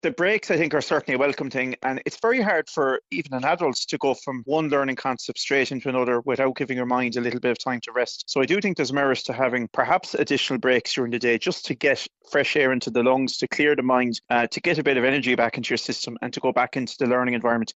Fianna Fáil TD and chair of the Education Committee, Cathal Crowe says 15-minute breaks between lessons would be helpful: